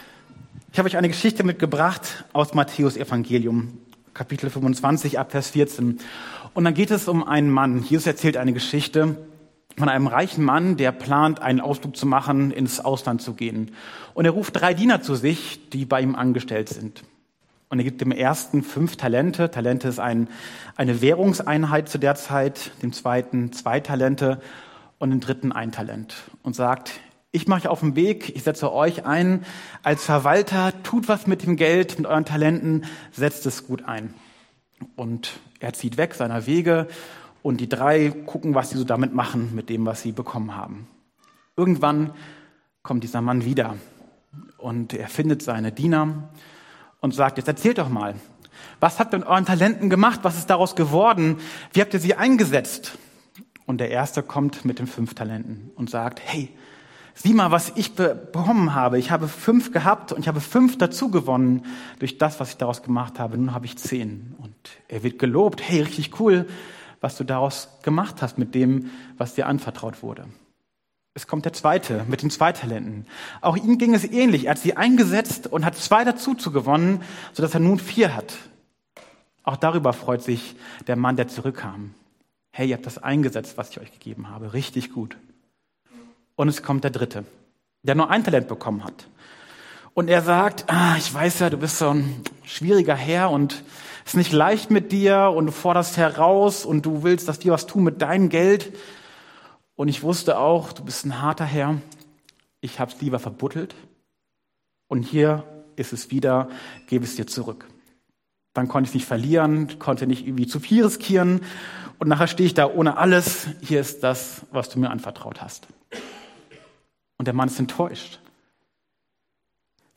Entfache sie neu in dir Passage: Matthäus 25, 14ff Dienstart: Predigt « Wie kann ich den Heiligen Geist spüren?